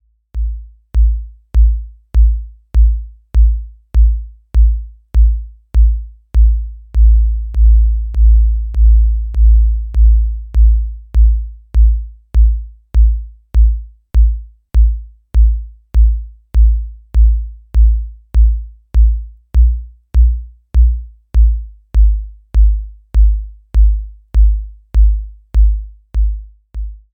Pretty minimal CPU and still gives you lots of control and 808 like boom.
The feedback loop into Cutoff Modulation can create some pretty beefy bass sounds.